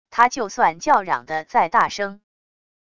他就算叫嚷的再大声wav音频生成系统WAV Audio Player